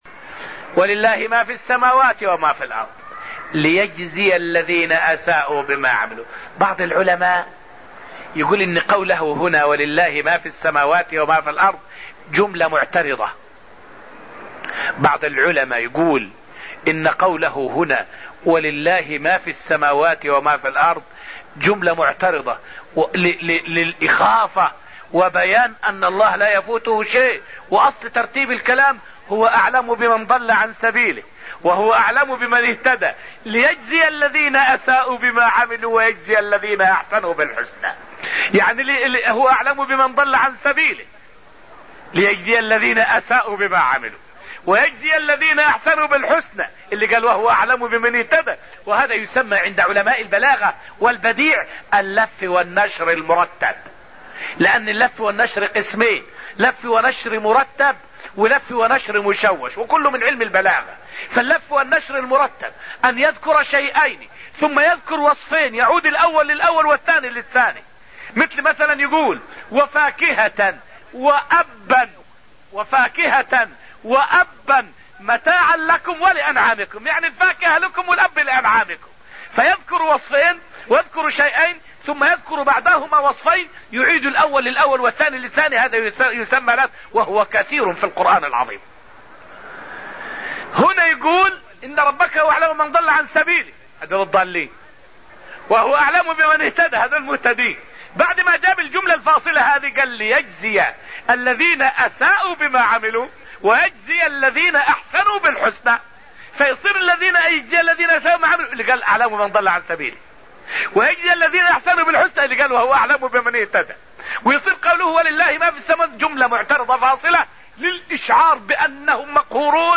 فائدة من الدرس العاشر من دروس تفسير سورة النجم والتي ألقيت في المسجد النبوي الشريف حول اللف والنشر وأقسامه عند أهل البلاغة واللغة.